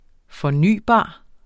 Udtale [ fʌˈnyˀˌbɑˀ ]